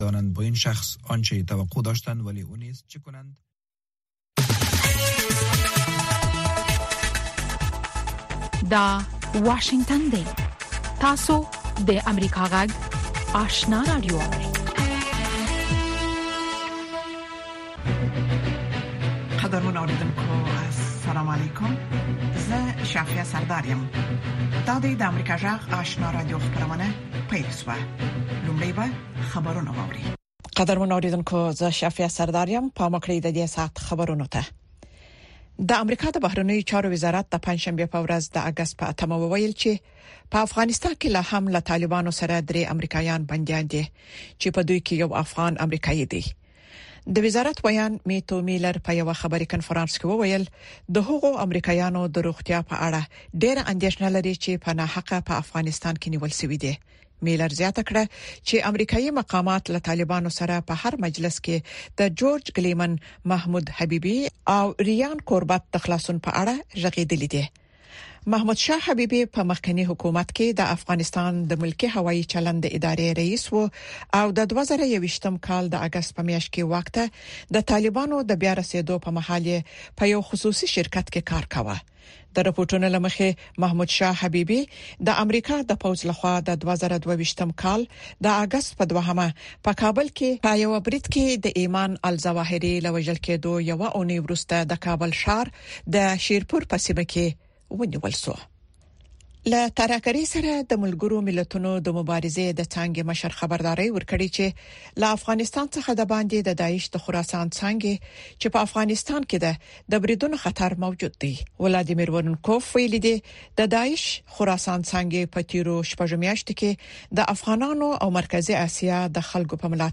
دویمه سهارنۍ خبري خپرونه
په سهارنۍ خپرونه کې د افغانستان او نړۍ تازه خبرونه، څیړنیز رپوټونه او د افغانستان او نړۍ د تودو پیښو په هکله مرکې تاسو ته وړاندې کیږي.